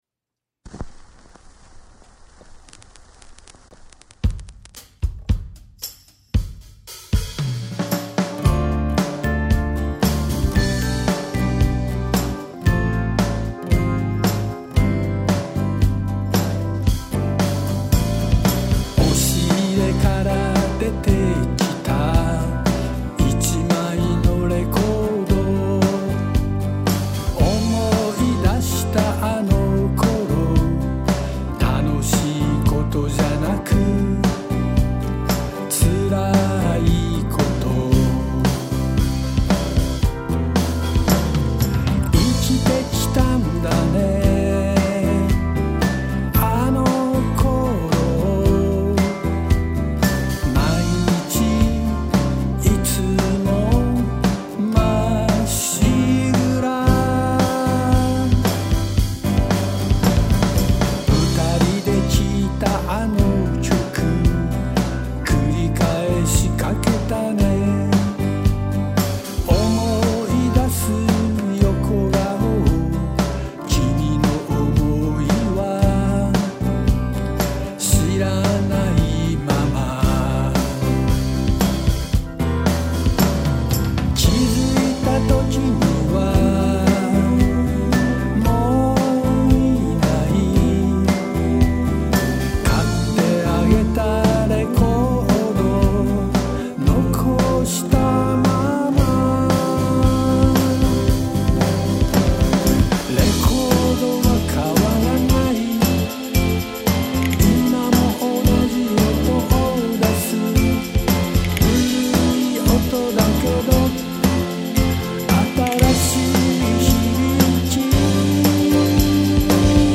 画像はライブのものですが音声はレコーディングしたものです
歌い手を目指しているわけではないので歌唱力はないです